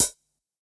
Index of /musicradar/ultimate-hihat-samples/Hits/ElectroHat D
UHH_ElectroHatD_Hit-30.wav